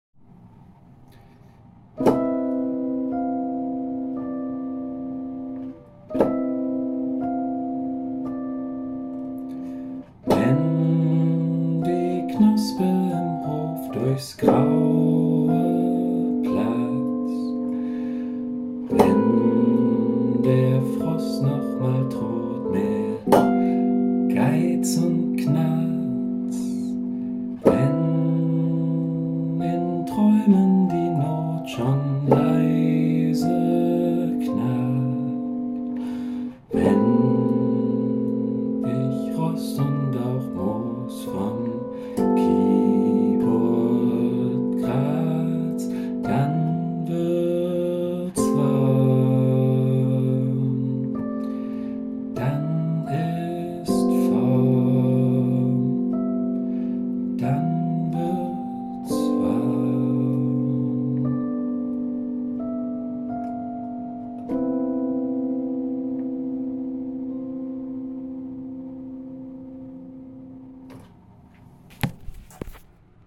Well, not really but sounds nice and soothing like a lullaby
so mellow
Almost hypnotic!
Einfach und prägnant - hat was von einem Chanson, find ich.